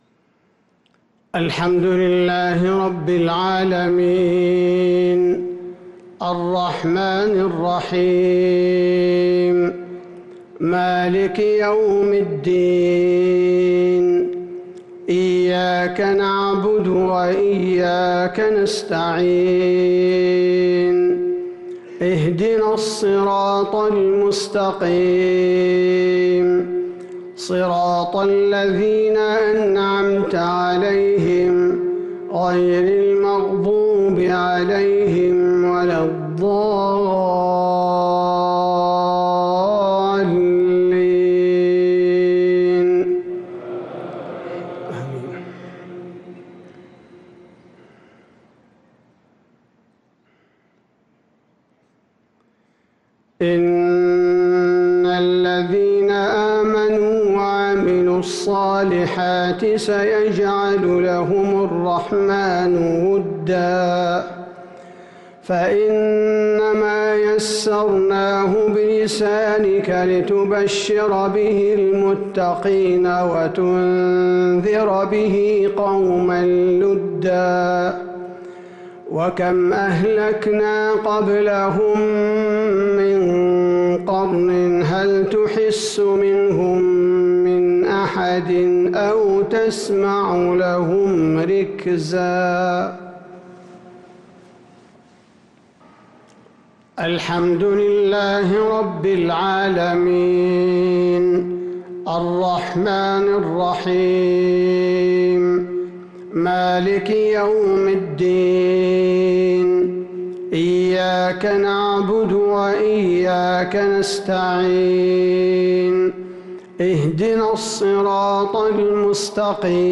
صلاة المغرب للقارئ عبدالباري الثبيتي 11 ذو القعدة 1443 هـ